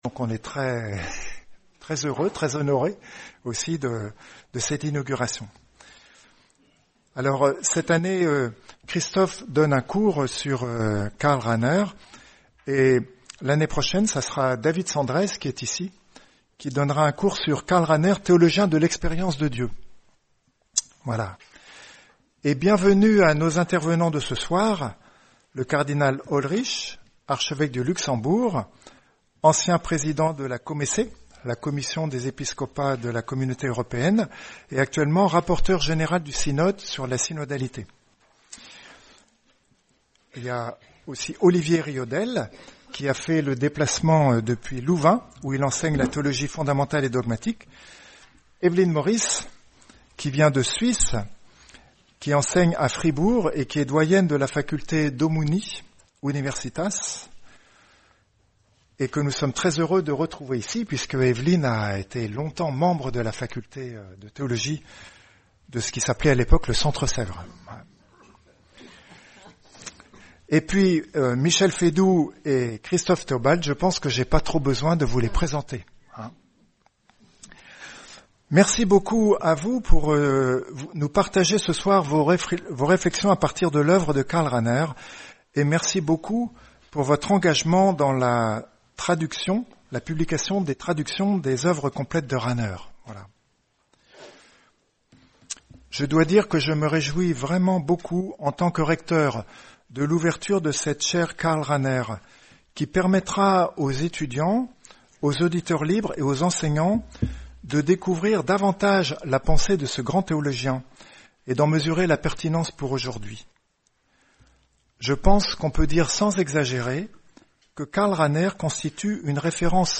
Karl Rahner est un des rares théologiens du 20e siècle qui a pris très tôt conscience des mutations qui nous attendent et qui les a pensées. Lors de cette soirée, on discutera quelques-unes de ses propositions en présentant en même temps trois volumes de l’édition critique de ses Œuvres, parus en 2022 et 2023.